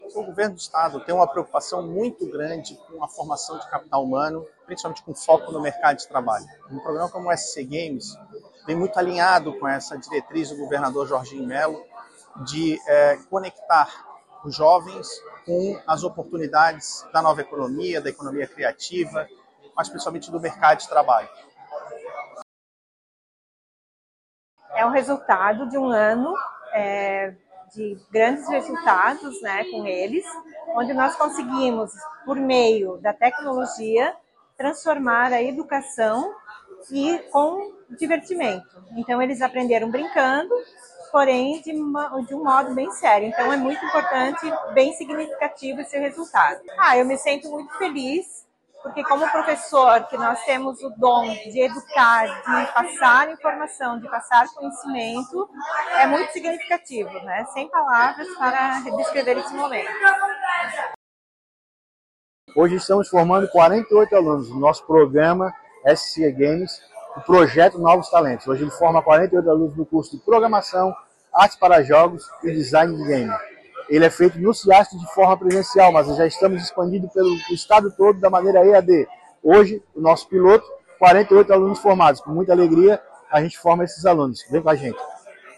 O auditório do campus da Univali, do bairro Saco Grande, em Florianópolis, ficou pequeno nesta sexta-feira, 13, durante a formatura de 48 crianças e adolescentes que participaram do projeto Novos Talentos, do Governo do Estado.
O secretário de Estado da Ciência, Tecnologia e Inovação, Marcelo Fett, ressaltou a importância do projeto e da formatura para os alunos: